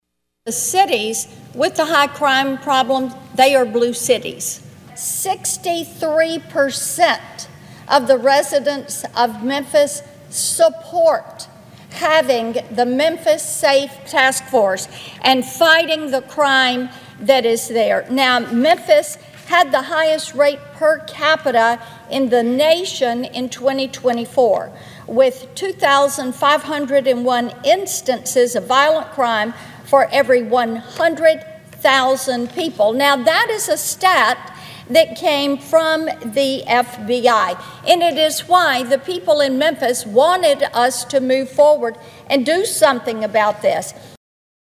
Following the block, Senator Blackburn slammed the move by Democratic lawmakers.(AUDIO)